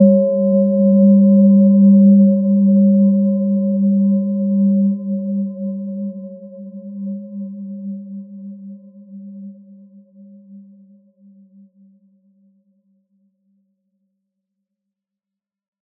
Gentle-Metallic-4-G3-mf.wav